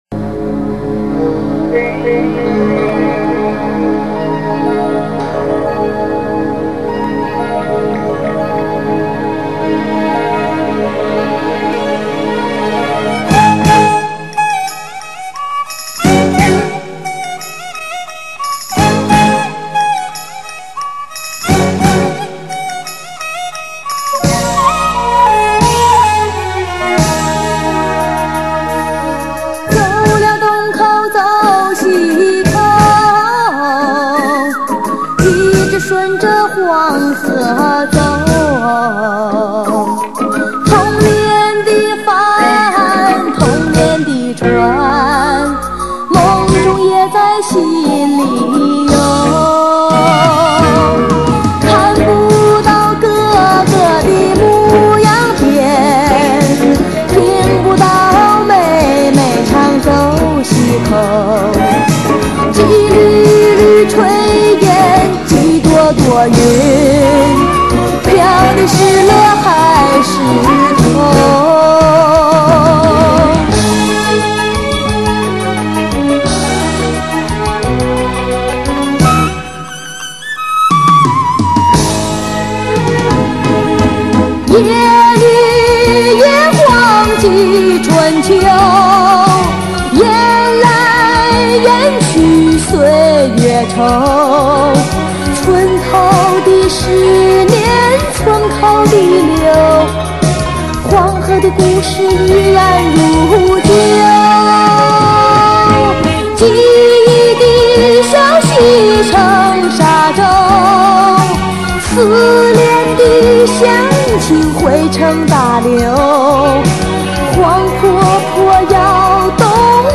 我这里有一首，只是音质不太好。